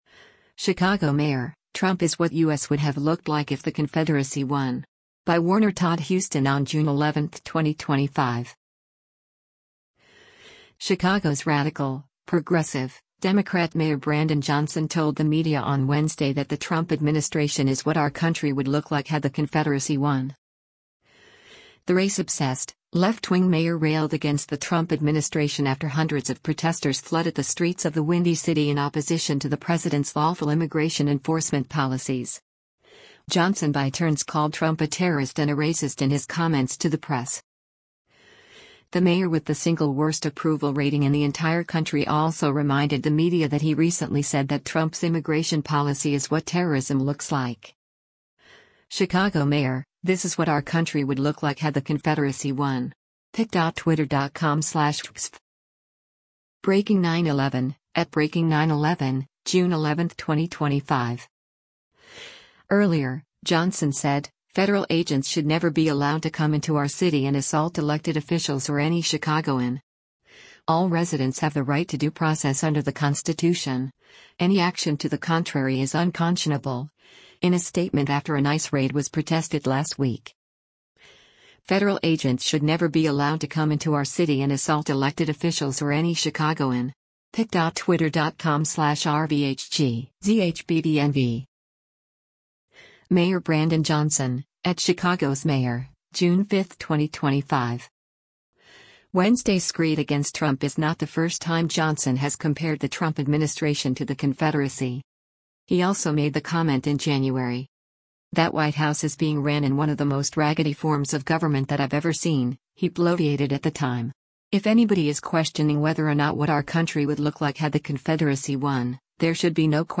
Chicago’s radical, progressive, Democrat Mayor Brandon Johnson told the media on Wednesday that the Trump administration is “what our country would look like had the Confederacy won.”
Johnson by turns called Trump a “terrorist” and a “racist” in his comments to the press.